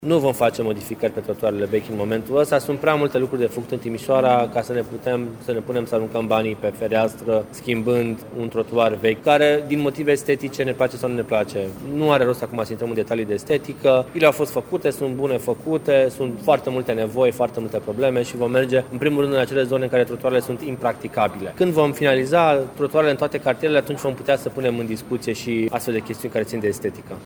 Autoritățile locale vor să intervină la acele trotuare din oraș aflate într-o stare avansată de degradare, spune viceprimarul Ruben Lațcău.